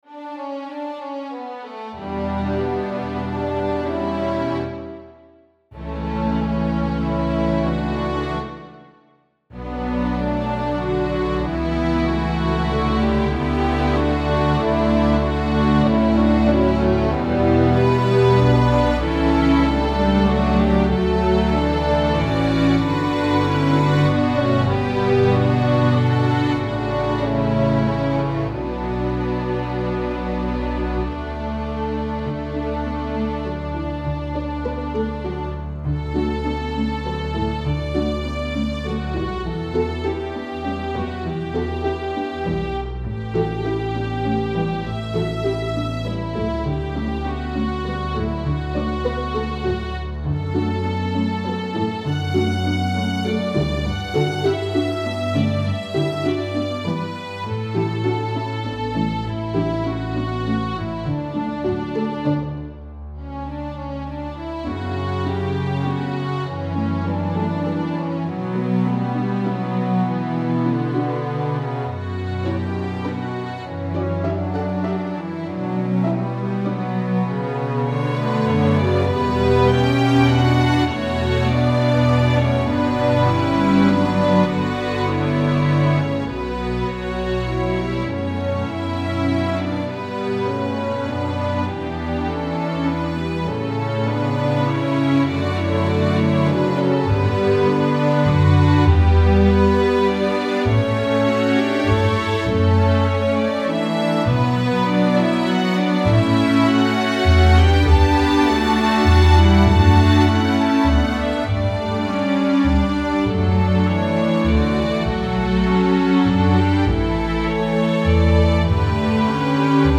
Sweet, hopeful, uplifting, and inspiring
Instrumentation: Violin 1 2, Viola, Cello, Double Bass